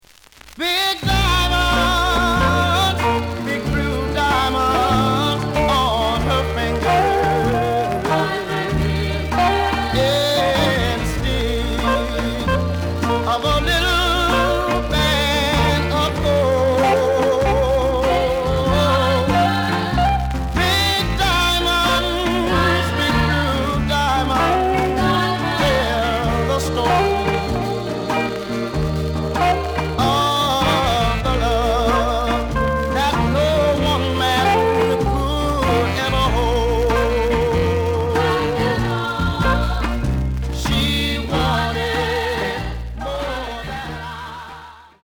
The audio sample is recorded from the actual item.
●Format: 7 inch
●Genre: Rhythm And Blues / Rock 'n' Roll
Some noise on both sides.)